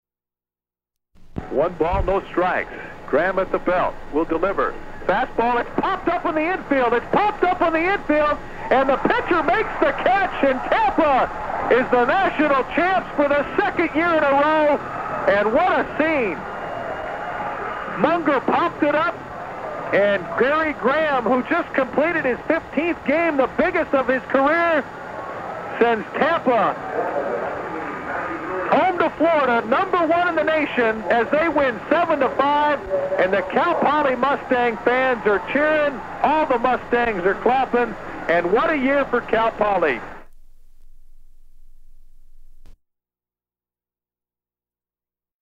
Clip of a play-by-play call of a Cal Poly sports event.
Form of original Audiocassette